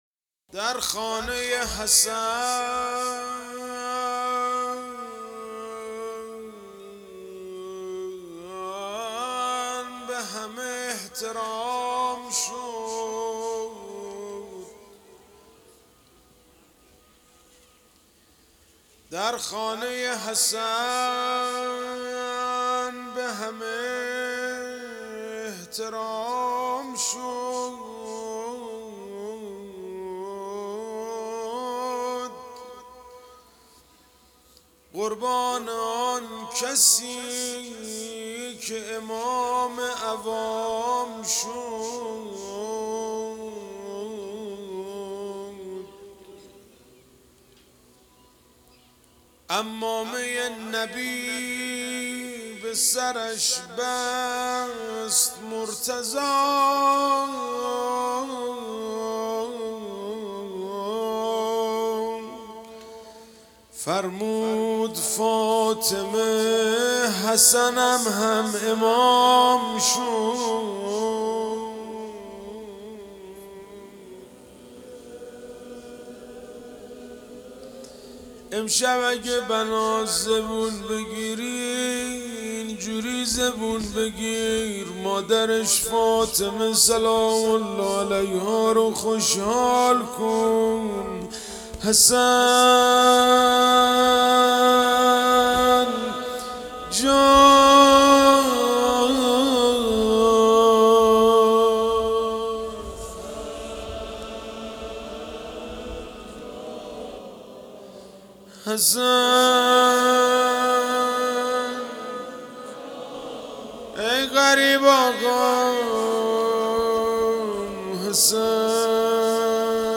محرم1400 - شب ششم - روضه - درخانه حسن به همه احترام شد